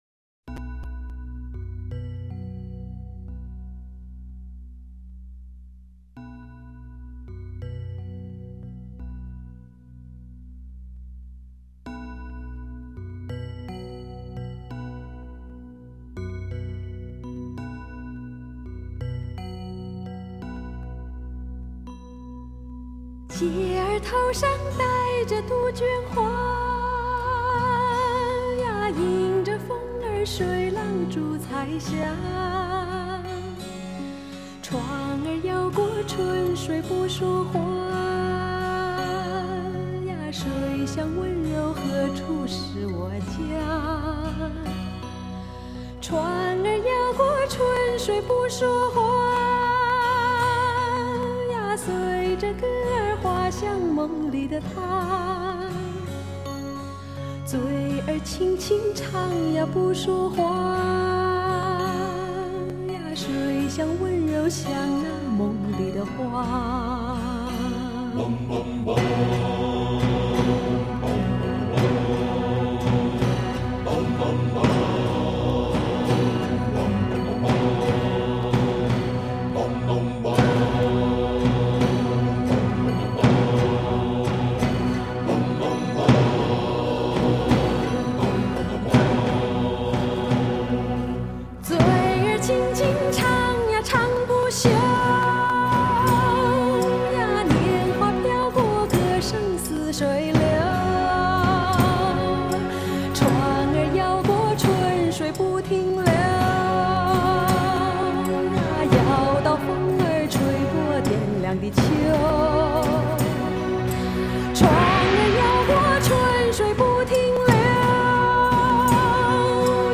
让天后、歌王级的组合为大伙儿献声对唱一曲